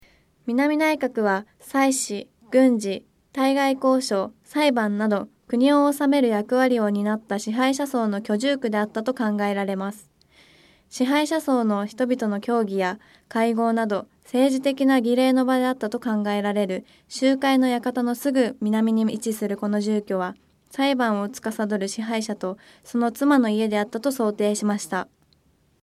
支配者層の人々の協議や会合など政治的な儀礼の場であったと考えられる「集会の館」のすぐ南に位置するこの住居は、裁判を司る支配者とその妻の家であったと想定しました。 音声ガイド 前のページ 次のページ ケータイガイドトップへ (C)YOSHINOGARI HISTORICAL PARK